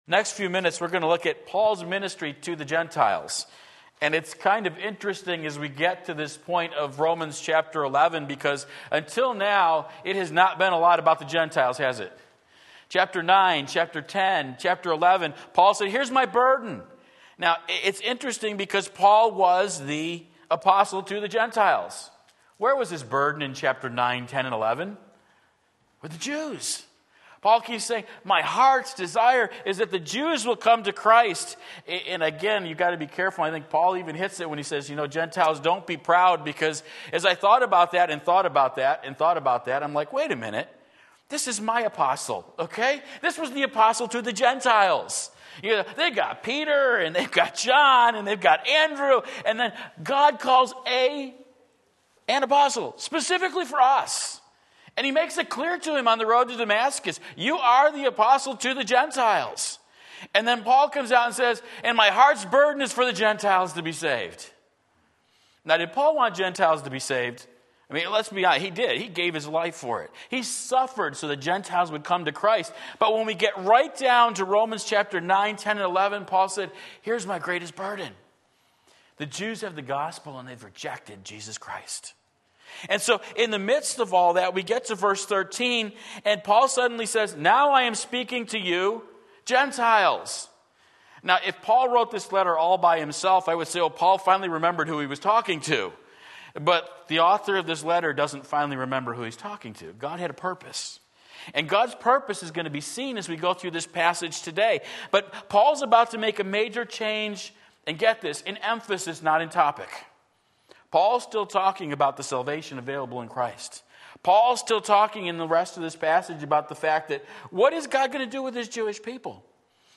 Sermon Link
Paul's Ministry to the Gentiles Romans 11:13-24 Sunday Morning Service